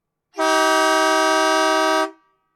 Industrial Air Horn
air alberta big horn industrial loud oil rig sound effect free sound royalty free Memes